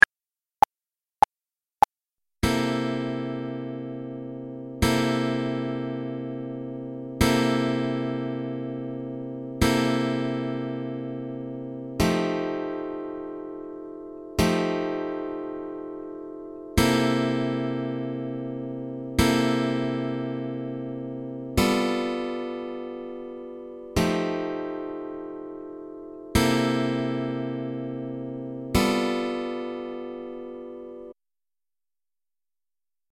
Example 2 – Key of C with Turnaround
This example uses C13, F9, and G9.
Basic 12 Bar Blues with Turnaround - Key of C